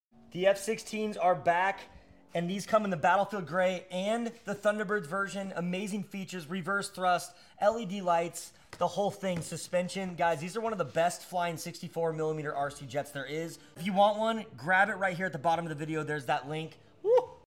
🔥The FMS F 16 64mm RC sound effects free download